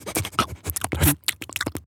dog_lick_smell_01.wav